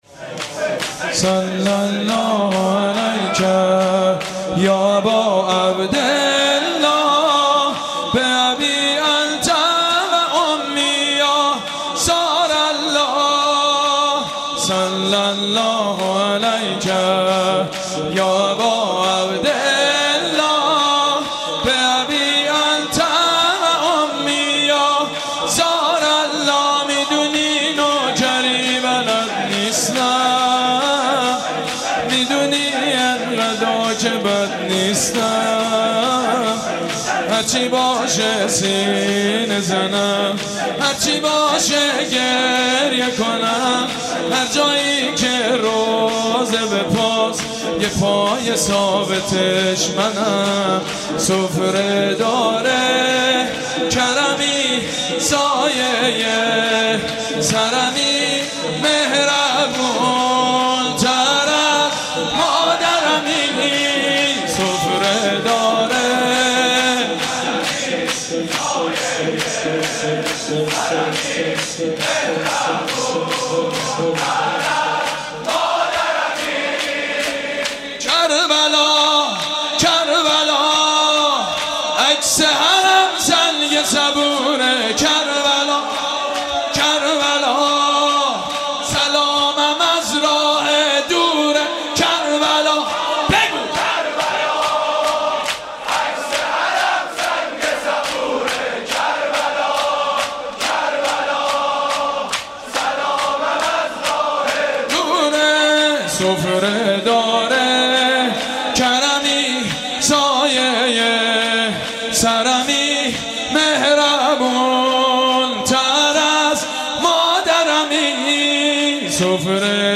تنگ غروبه شور کربلایی حسین طاهری